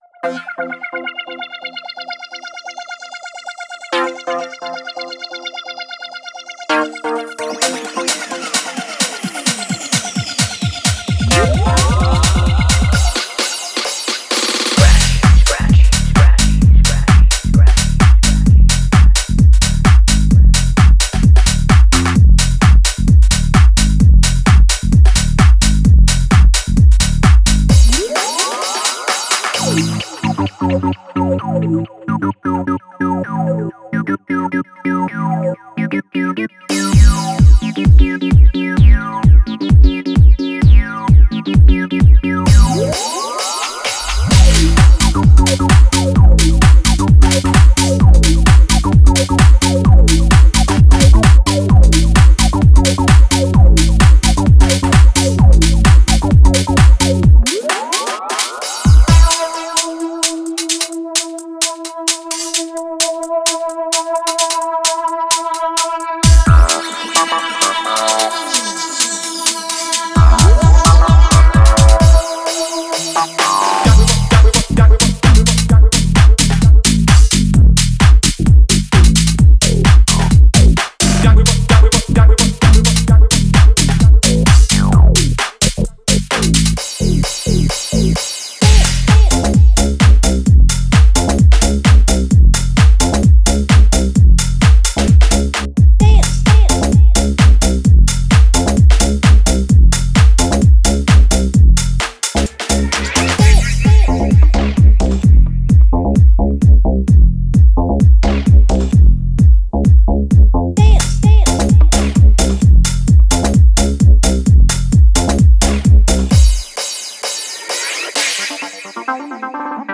rolling tunes
All loops play at 130 BPM.